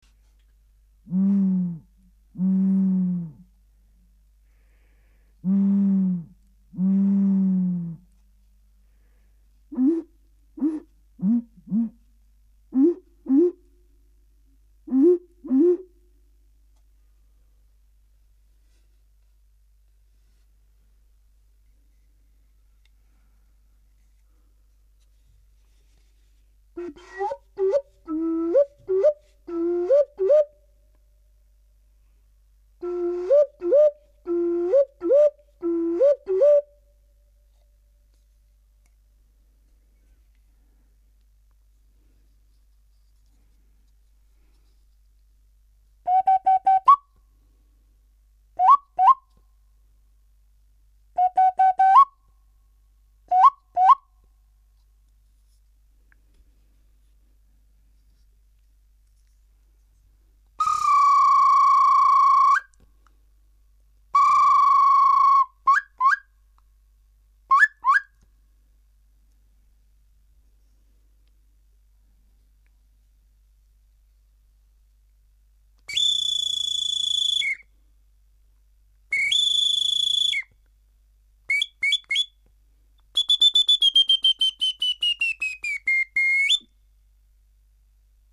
鳥笛
鳥笛は大きさがいろいろあり　大きくなるほど音が低くなります　筒の上下を両手で開け閉めして　音階を調整すると１オクターブでます